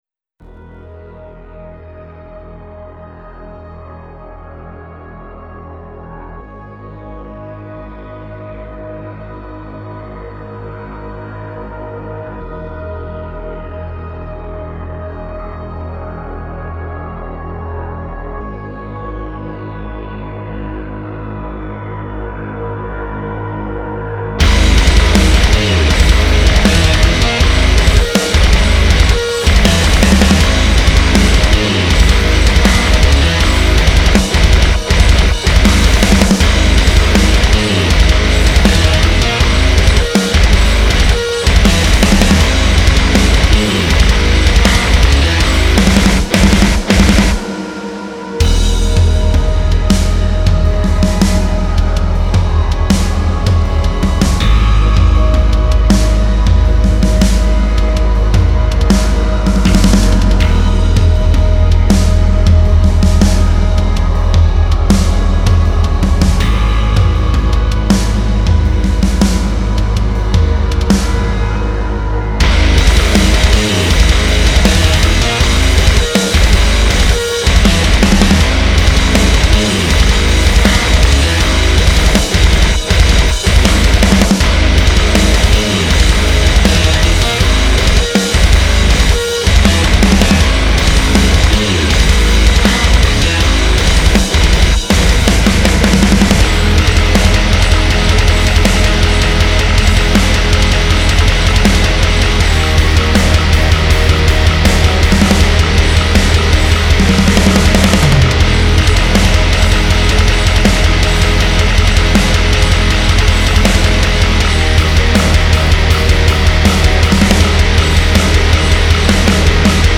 Here is my quick mix!)